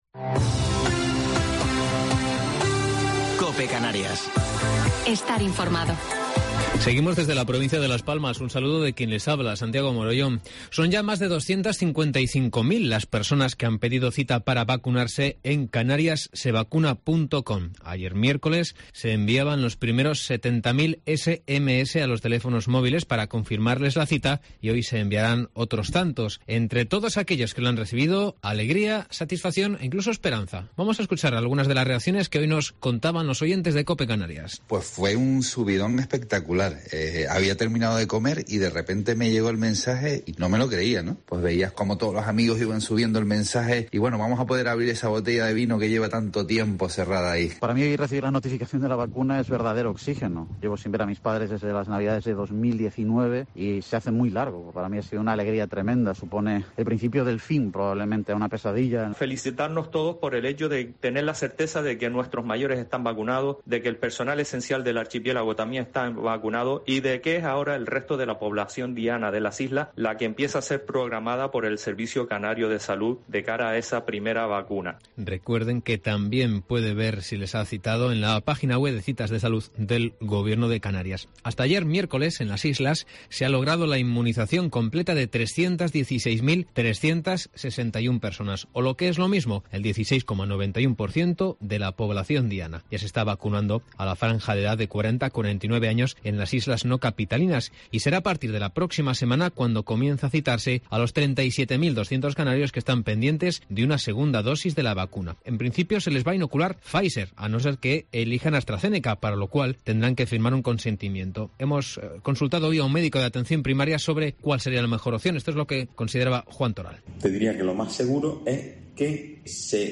Informativo local 27 de Mayo del 2021